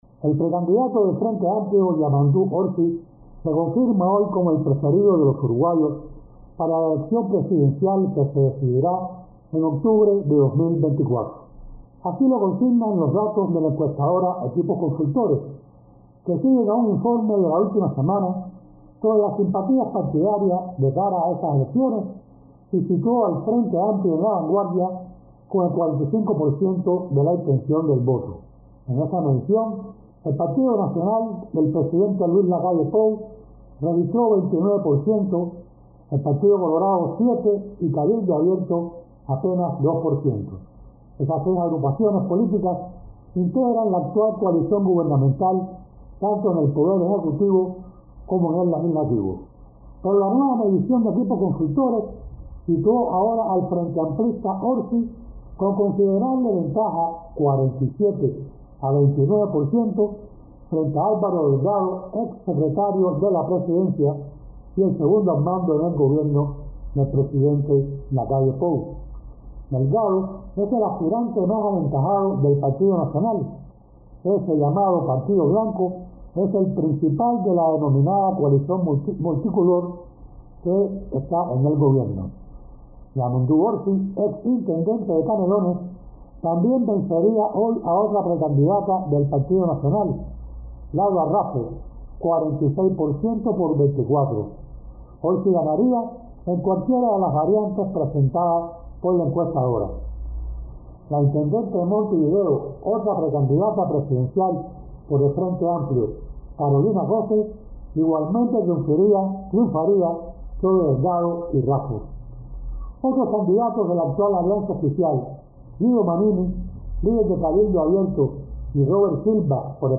desde Montevideo